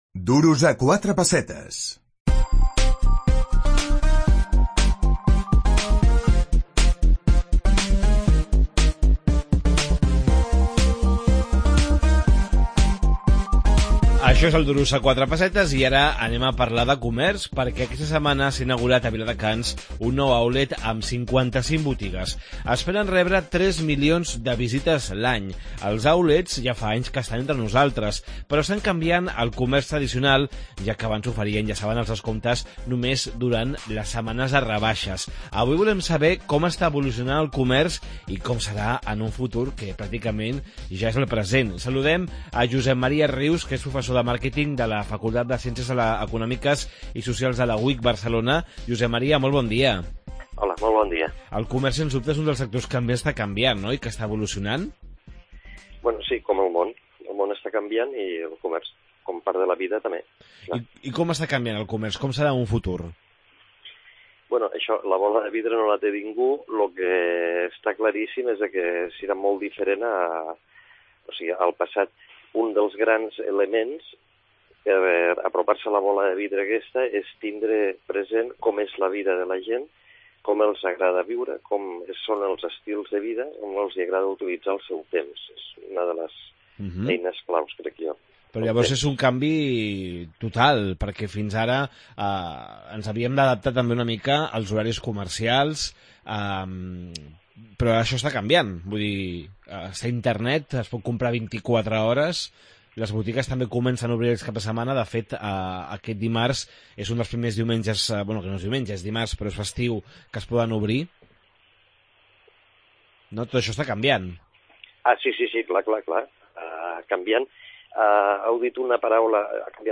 Com serà el futur del comerç? Entrevista